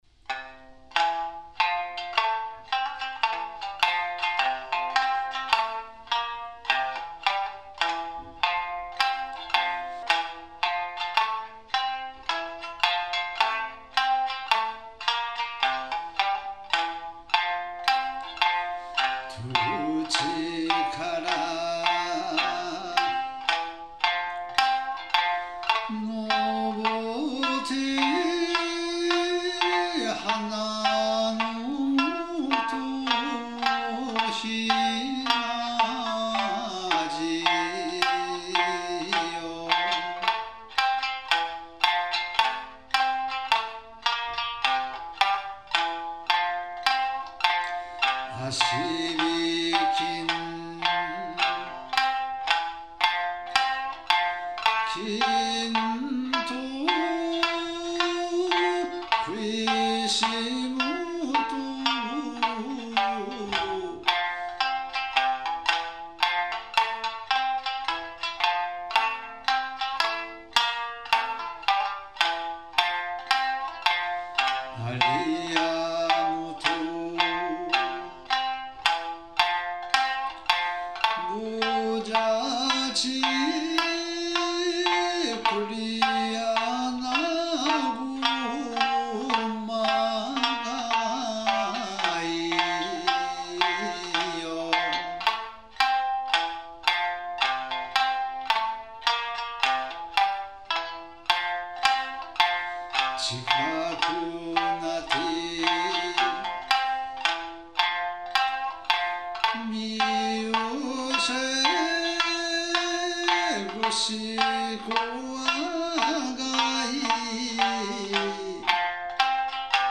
歌三線
三線　笛　太鼓　三板